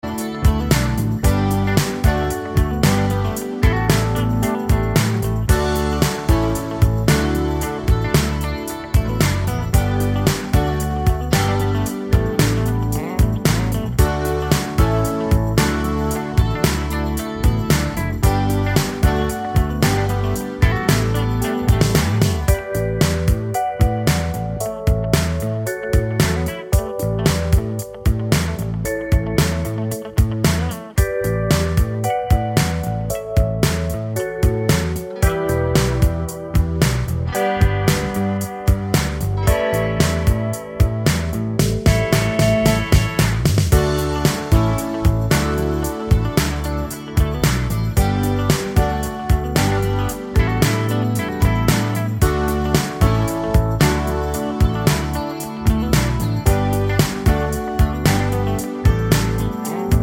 Professional Pop (1980s) Backing Tracks.